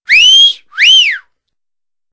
silbido_b.wav